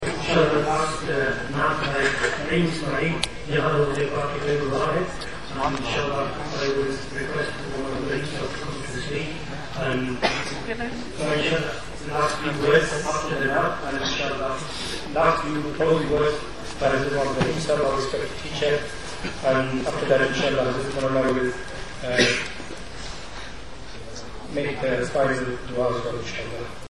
Bukhari Graduation 2008 Darul-Uloom, Madinatul-Uloom